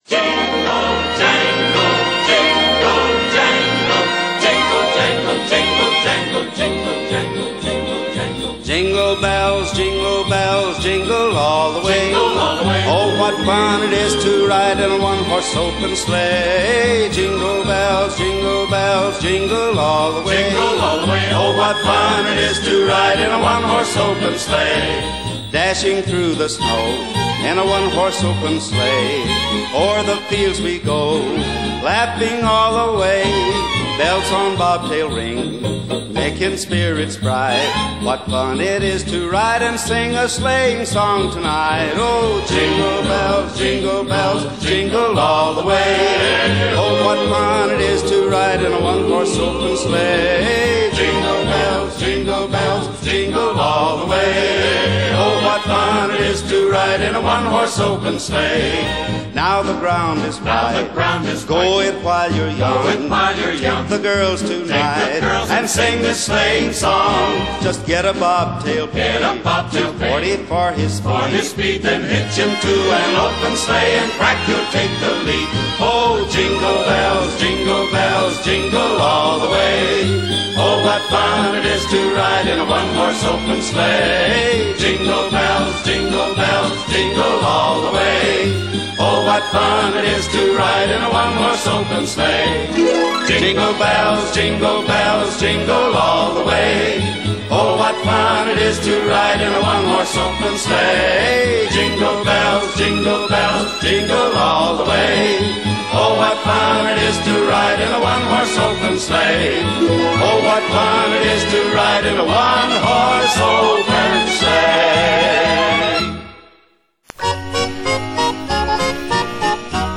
Quality: CBR 320 kbps Stereo